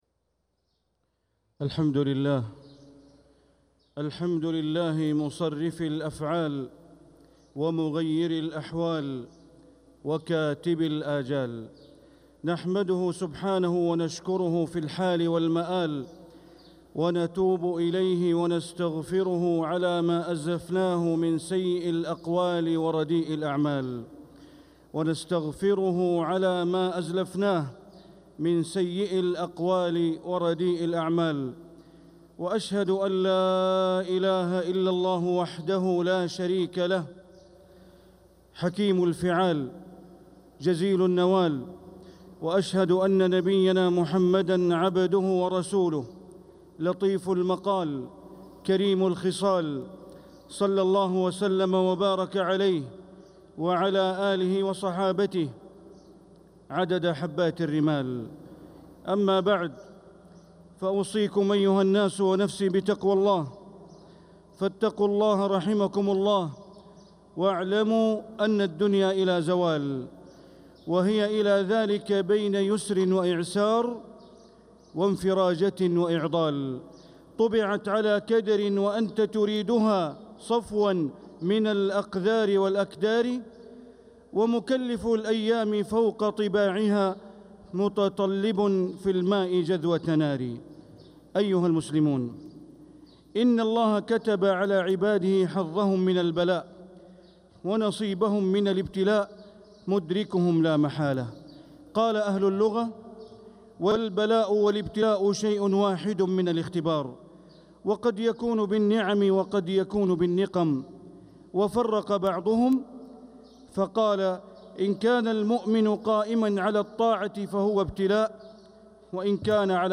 خطبة الجمعة ٢٥ ذو القعدة ١٤٤٦هـ > خطب الشيخ بندر بليلة من الحرم المكي > المزيد - تلاوات بندر بليلة